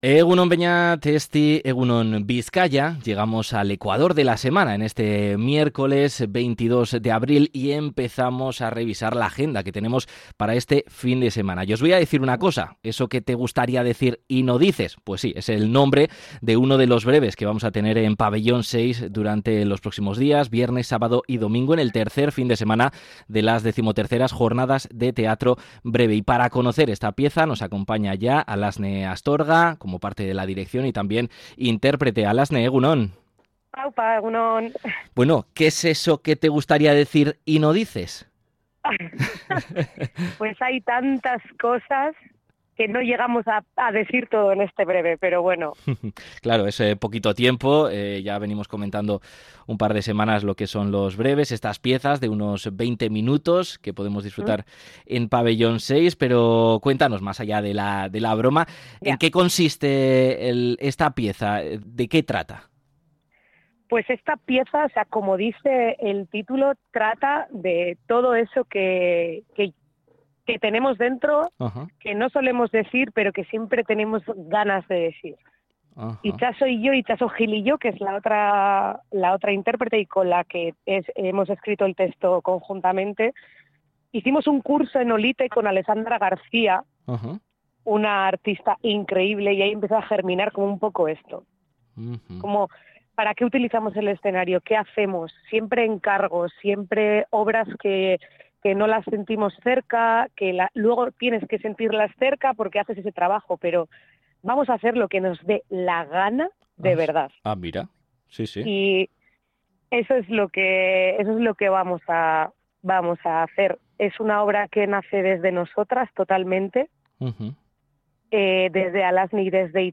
Ruptura del código teatral